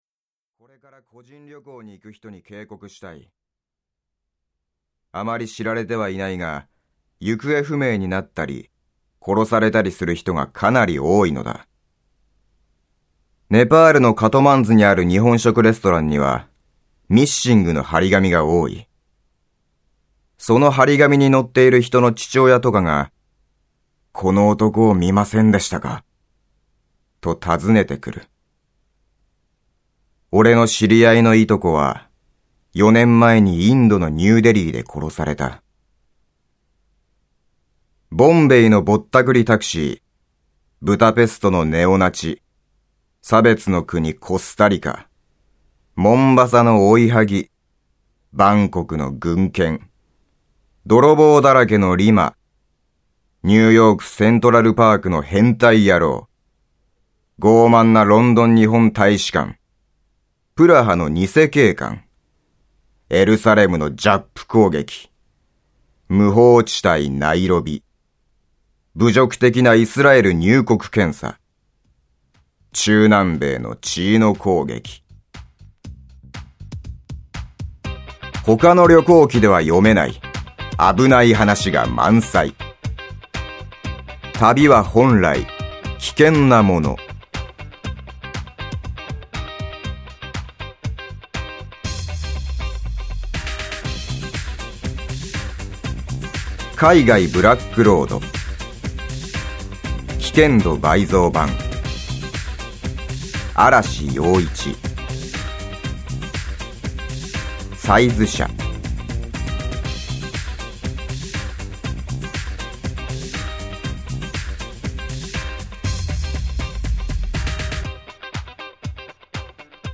[オーディオブック] 海外ブラックロード 危険度倍増版